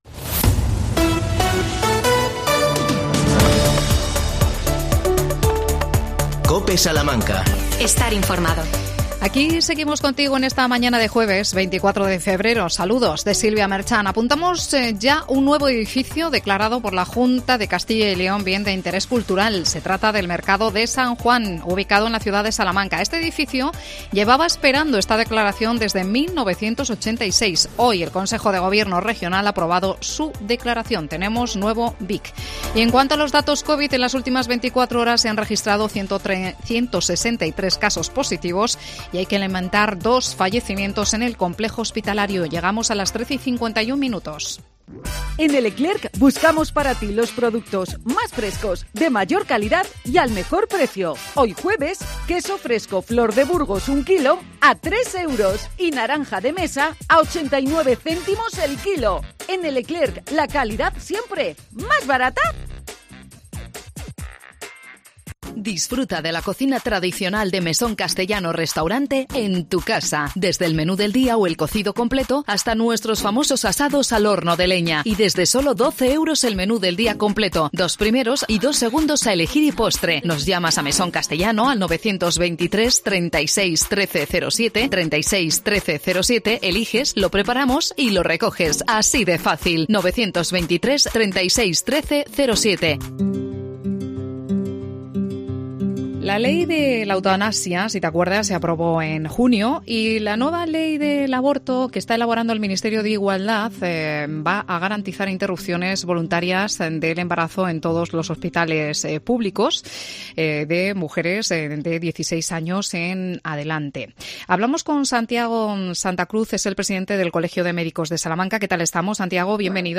Objeción de conciencia de los profesionales sanitarios en eutanasia y aborto. Entrevistamos